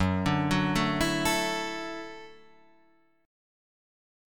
Gbm7#5 chord